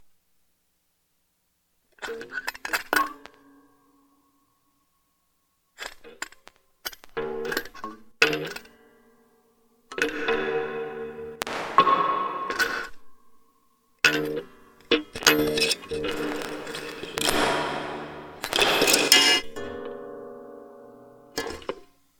a hybrid between edgy improv takes and deep IDM-ish grooves
All this was done on a live setup without a laptop.